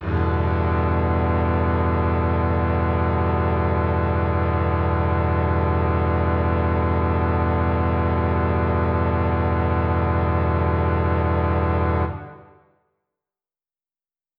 SO_KTron-Cello-Emin7.wav